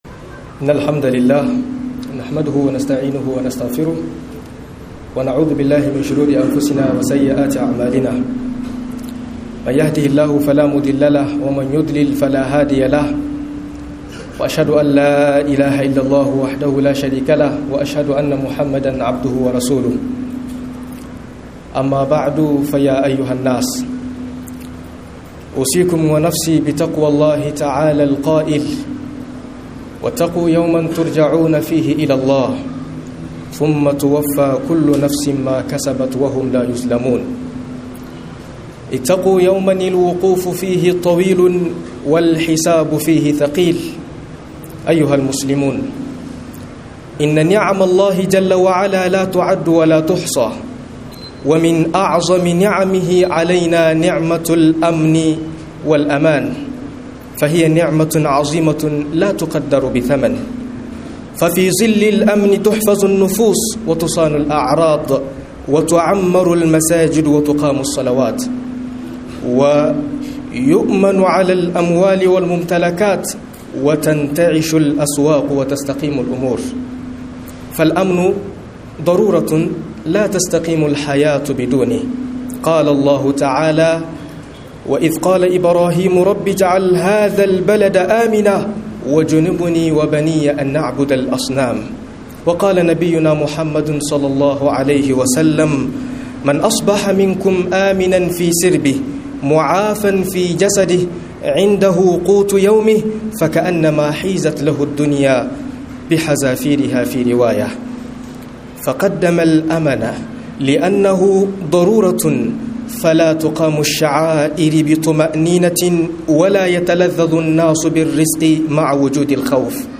Mahimmancin Zaman Lafia - MUHADARA